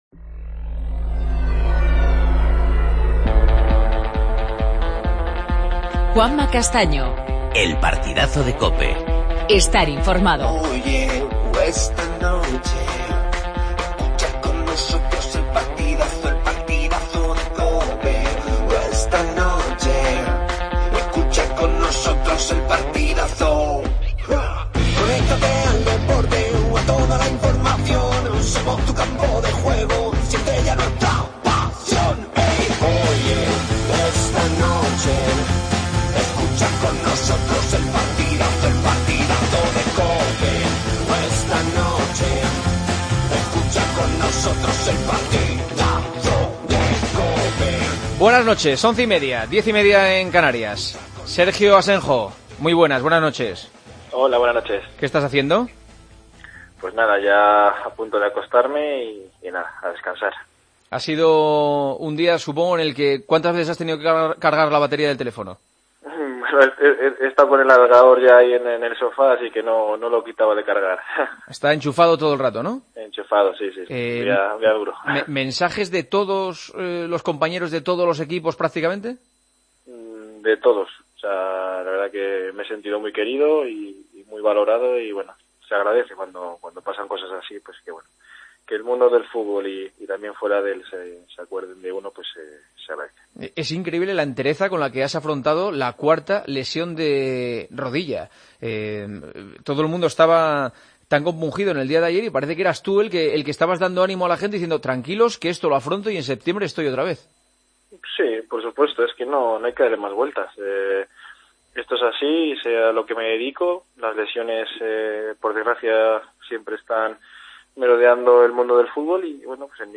AUDIO: Entrevista a Sergio Asenjo, portero del Villarreal. Titulares del día.